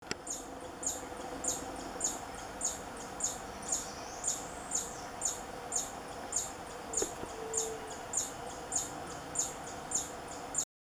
Picaflor Copetón (Stephanoxis loddigesii)
Nombre en inglés: Purple-crowned Plovercrest
Fase de la vida: Adulto
Localidad o área protegida: Parque Provincial Salto Encantado
Condición: Silvestre
Certeza: Fotografiada, Vocalización Grabada